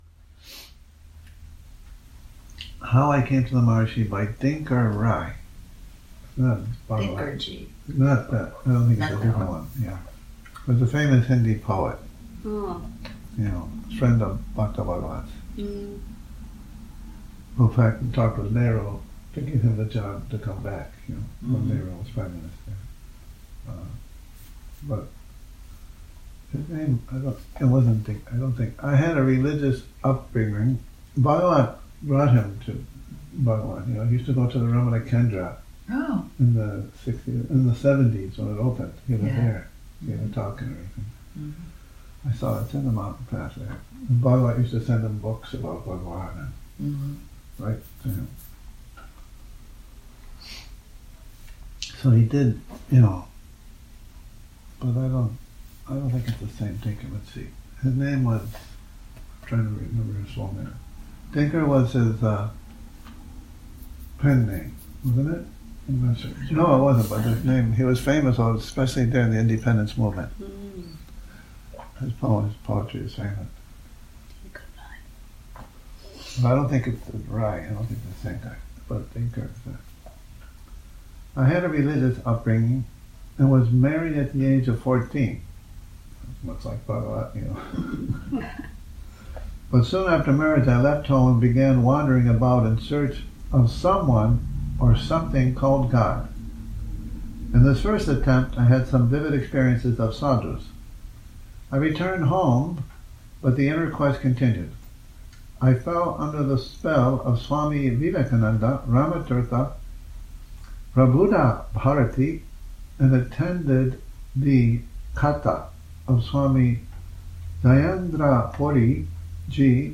Morning Reading, 25 Nov 2019